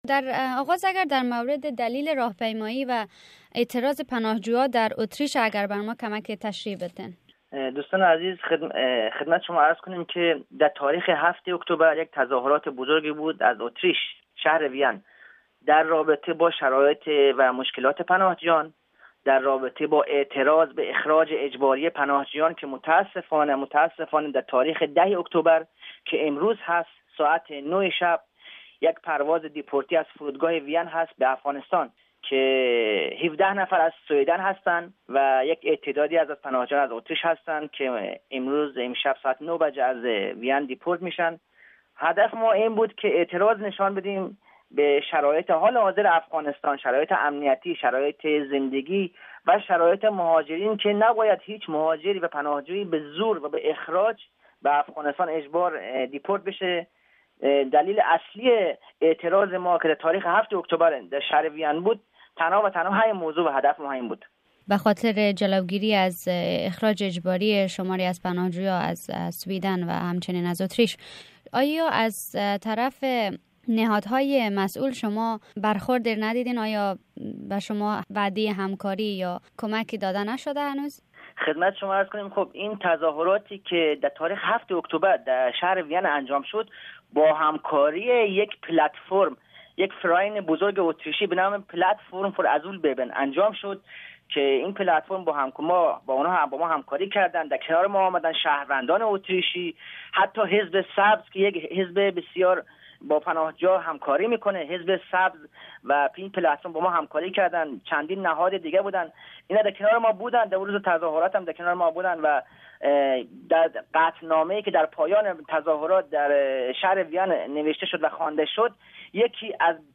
جریان صحبت